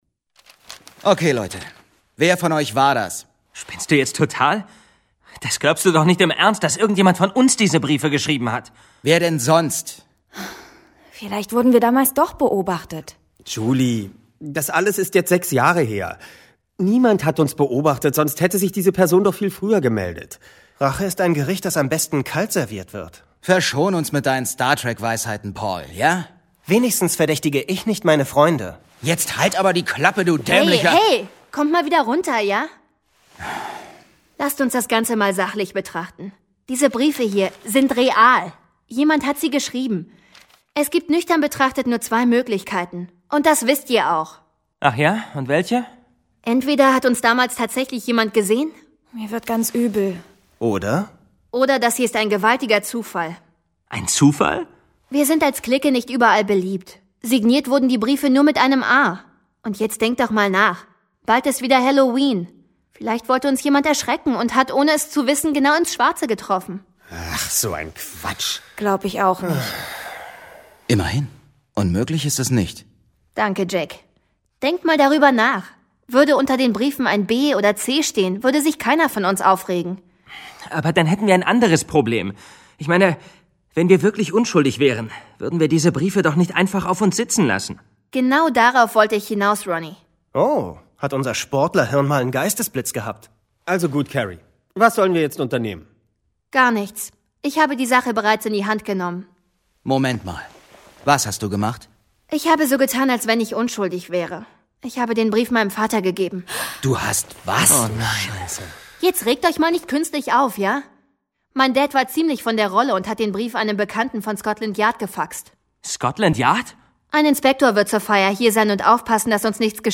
John Sinclair - Folge 42 Blutiger Halloween. Hörspiel Jason Dark